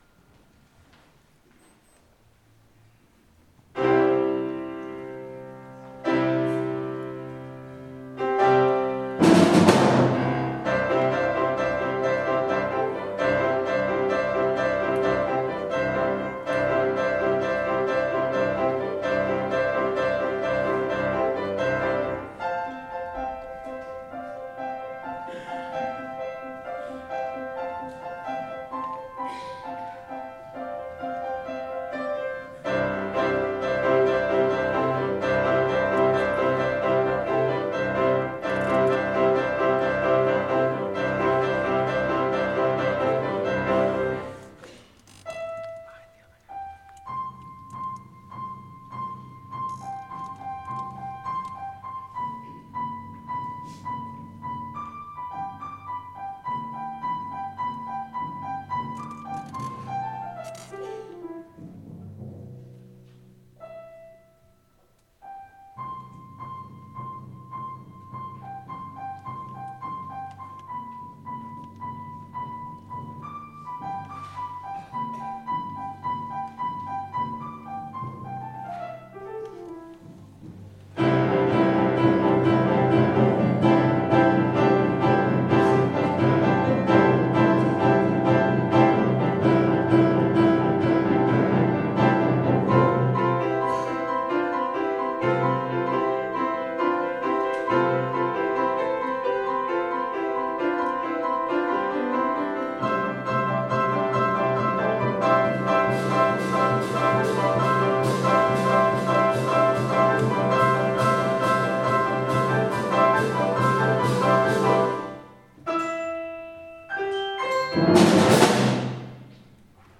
our May 4th concert
Tanz – instrumental